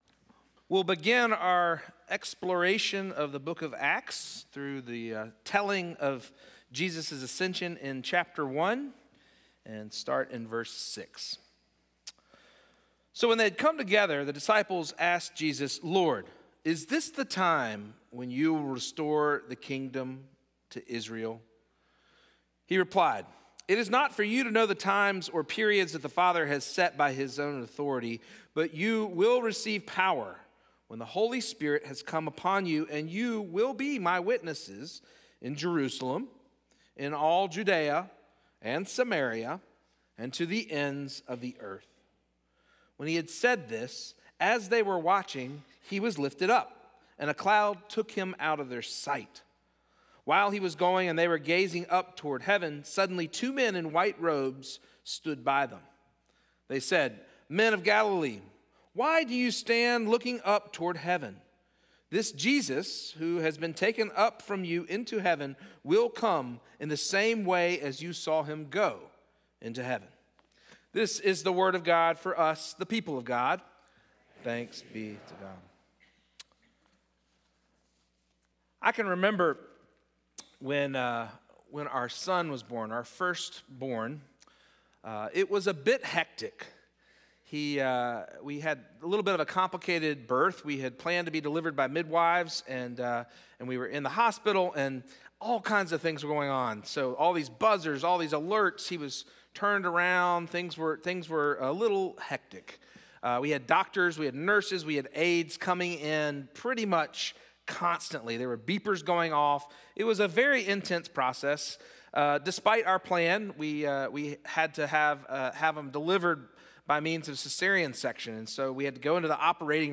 First Cary UMC's First Sanctuary Sermon